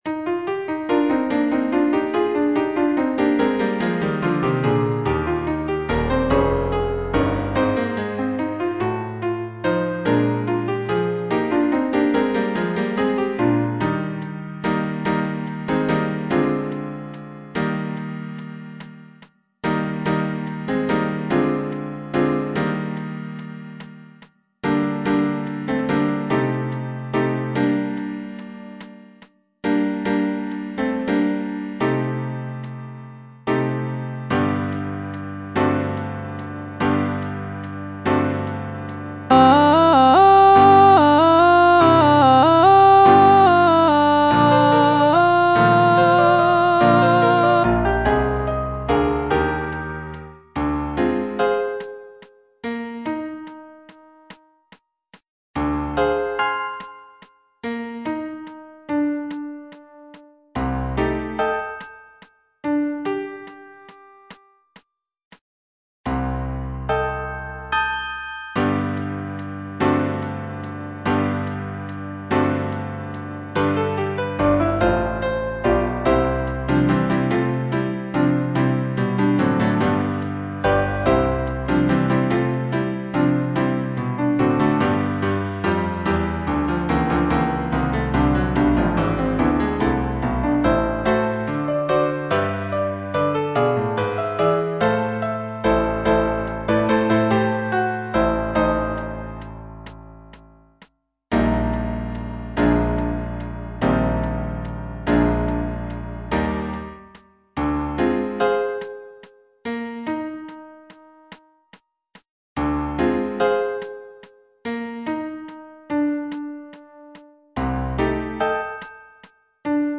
E. ELGAR - FROM THE BAVARIAN HIGHLANDS On the alm (alto II) (Voice with metronome) Ads stop: auto-stop